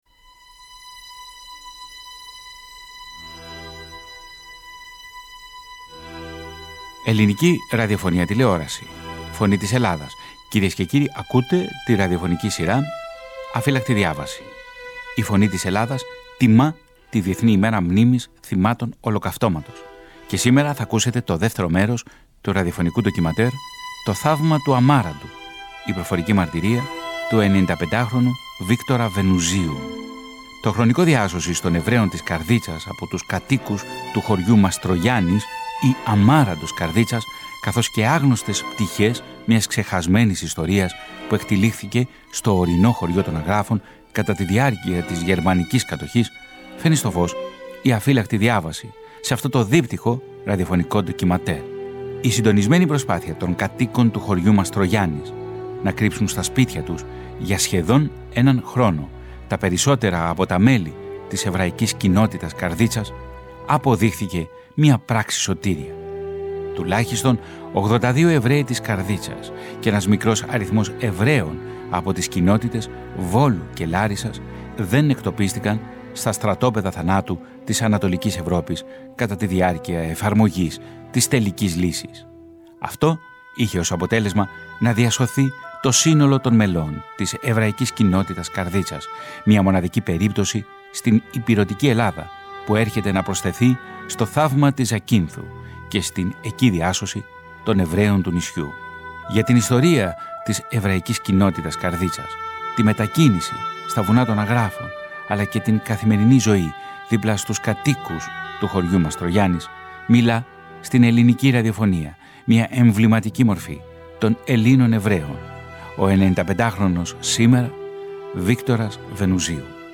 Ντοκιμαντέρ